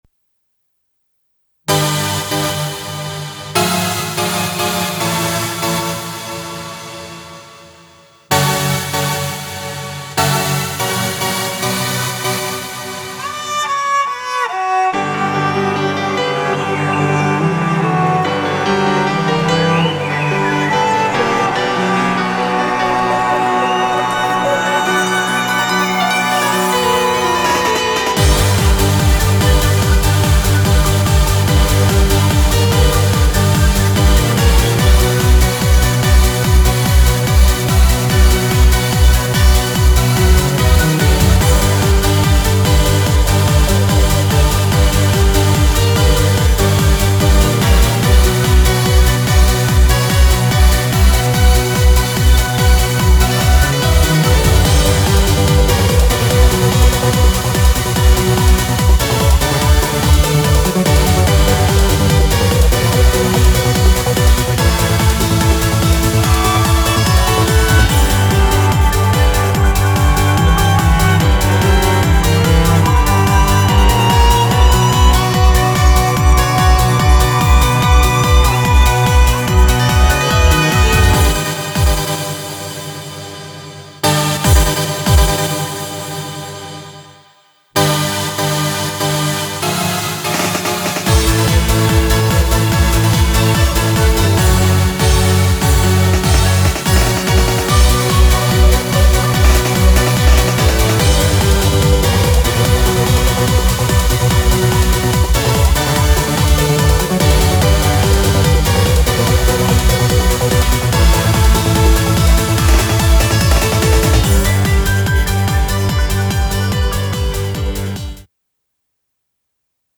BPM145
Audio QualityPerfect (High Quality)
Genre: Trance